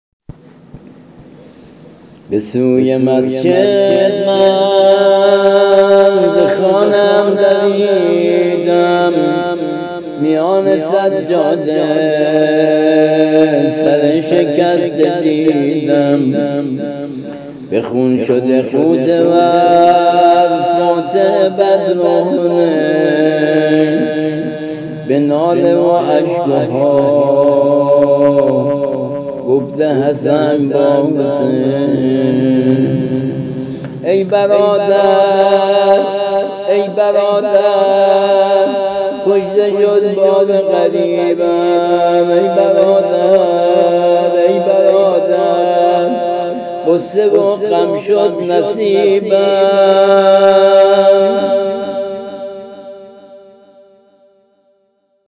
◾سبک به سمت گودال